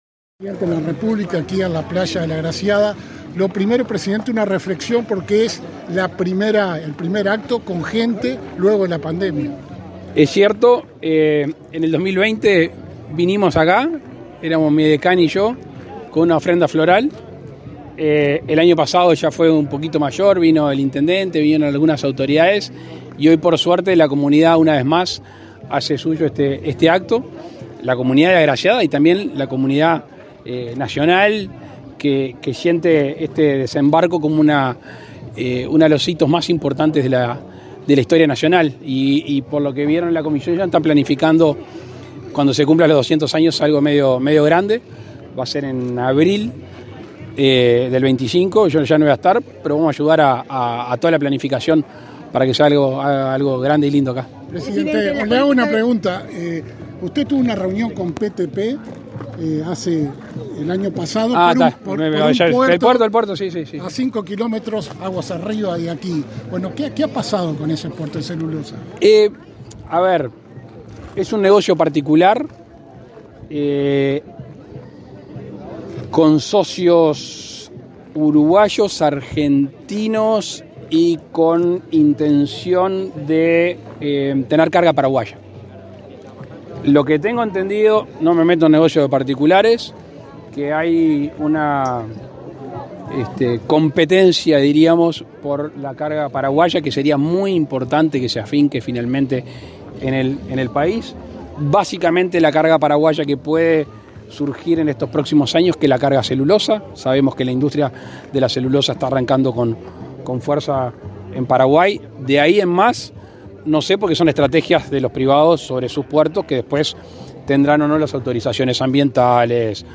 Declaraciones del presidente Lacalle Pou a la prensa
Este martes 19, el presidente Luis Lacalle Pou participó del acto aniversario del desembarco de los 33 Orientales en la playa de la Agraciada,